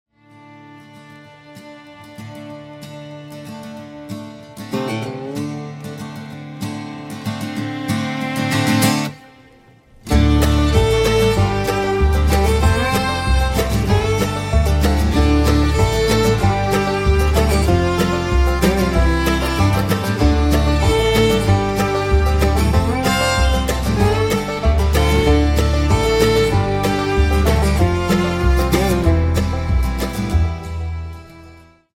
bluegrass